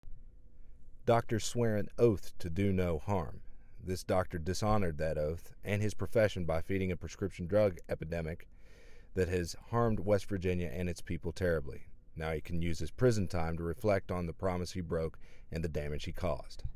Click the link below to listen to audio sound bite from U.S. Attorney Goodwin discussing today’s sentencing: